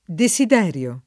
deSid$rLo] s. m.; pl.